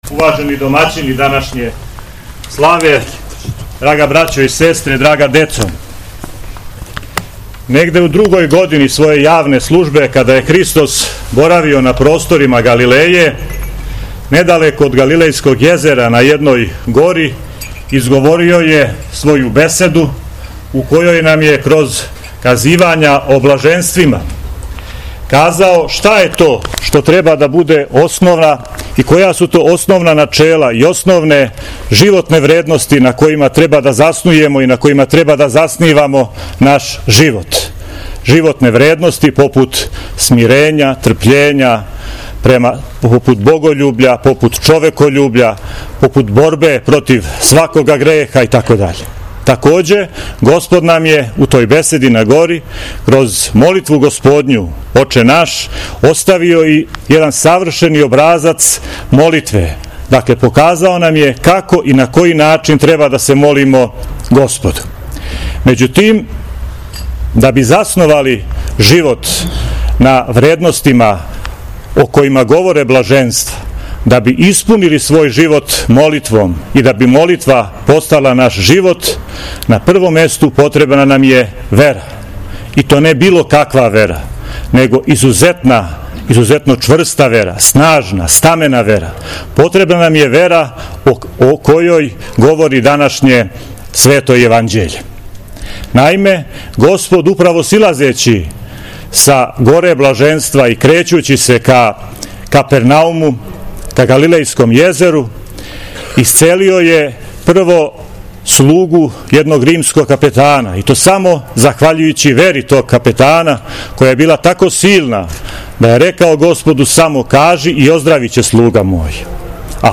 За певницом певали су Србски православни појци.